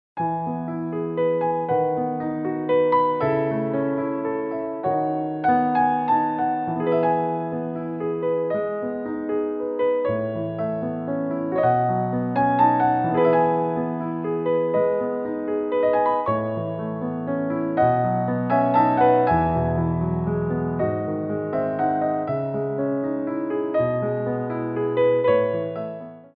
3/4 (8x8)